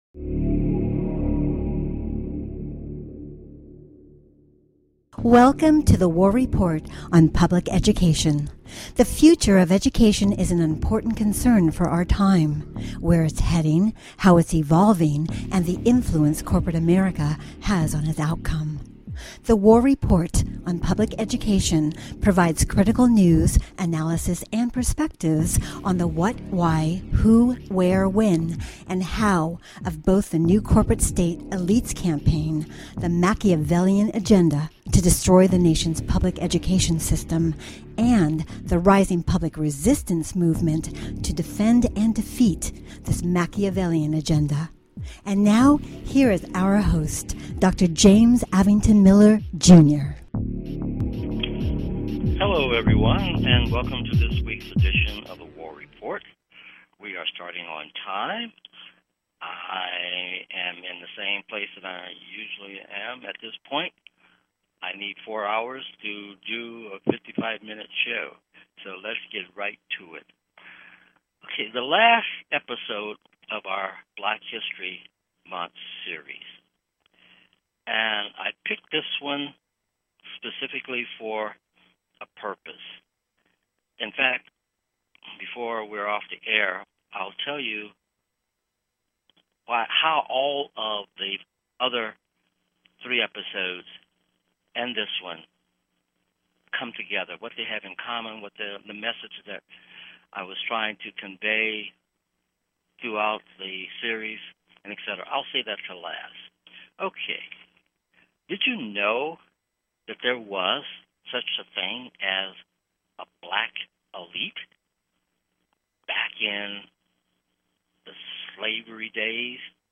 Talk Show Episode, Audio Podcast, The War Report on Public Education and BHM Part 4 - From Slavery to Reconstruction Elites to Jim Crow on , show guests , about BHM,Slavery,Reconstruction Elites,Jim Crow, categorized as Education,History,Kids & Family,News,Politics & Government,Society and Culture,Theory & Conspiracy